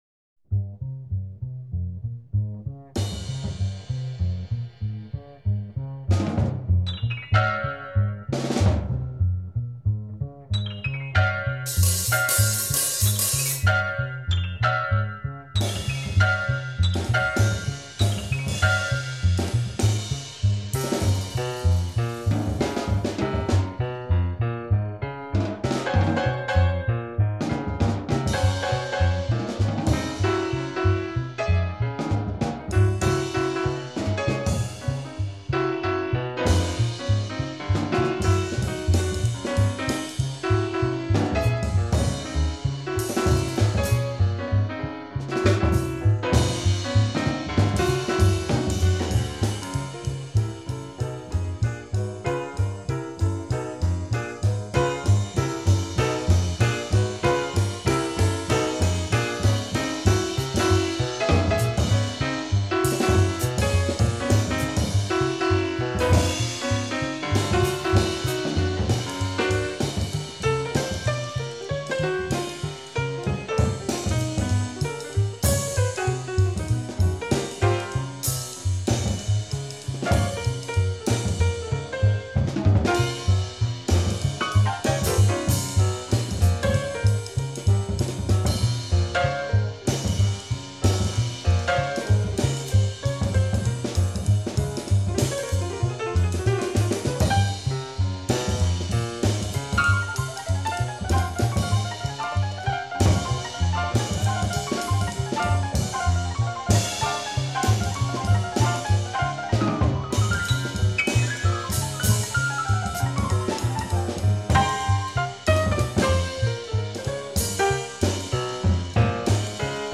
drummer
pianist
bassist